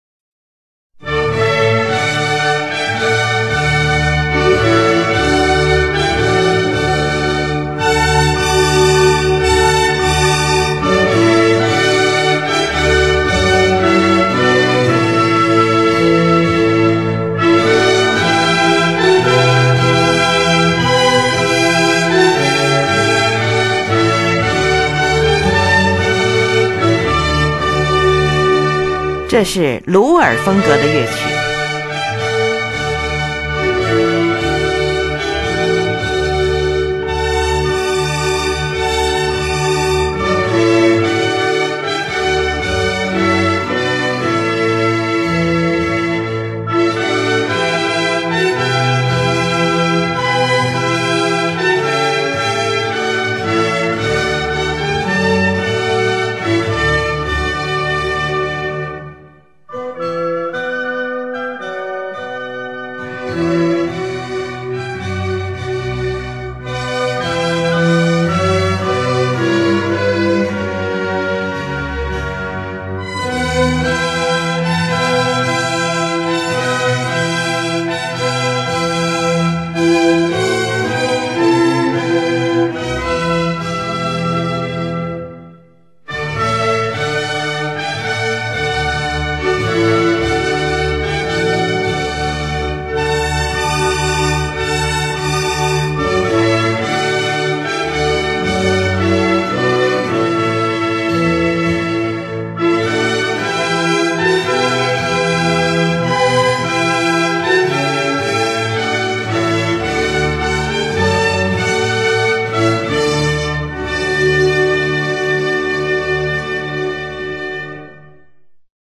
in F Major
是一部管弦乐组曲。